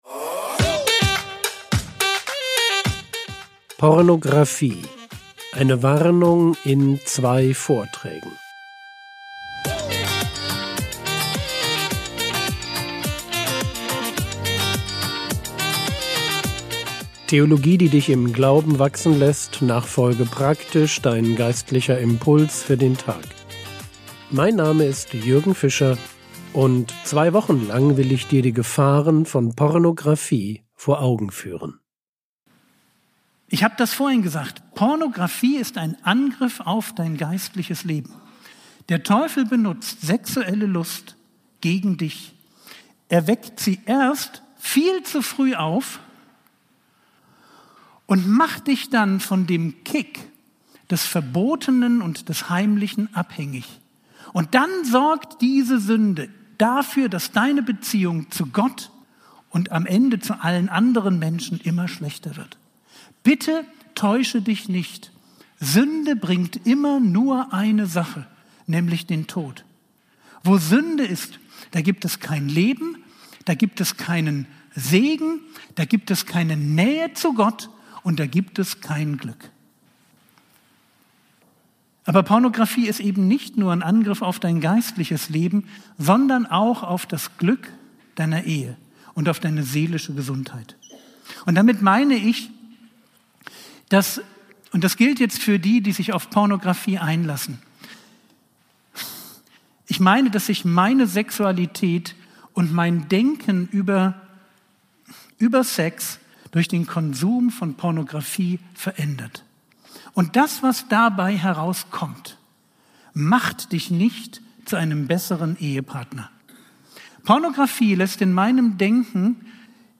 Frogwords Mini-Predigt